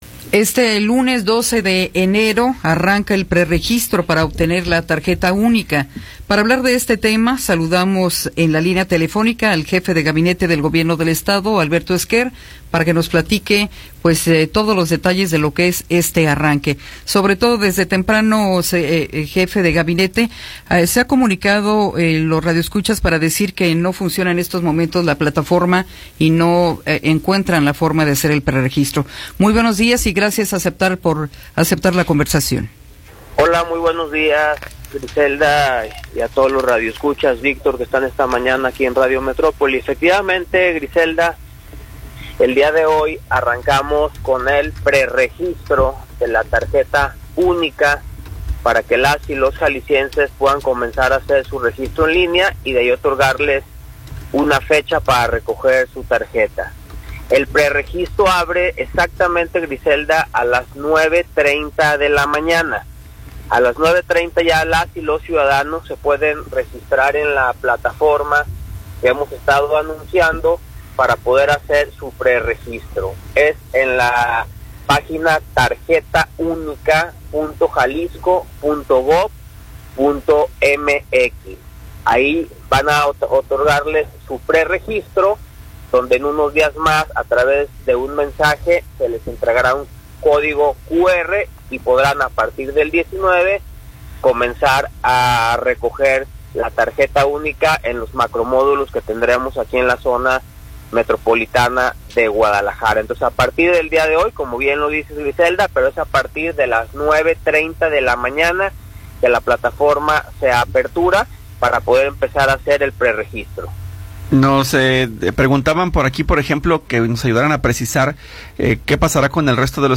Entrevista con Alberto Esquer Gutiérrez